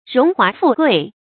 荣华富贵 róng huá fù guì 成语解释 荣华：兴旺显耀。
成语繁体 榮華富貴 成语简拼 rhfg 成语注音 ㄖㄨㄙˊ ㄏㄨㄚˊ ㄈㄨˋ ㄍㄨㄟˋ 常用程度 常用成语 感情色彩 中性成语 成语用法 联合式；作谓语、宾语、定语；含讽刺意味 成语结构 联合式成语 产生年代 古代成语 成语正音 华，不能读作“huà”。